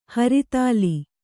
♪ haritāli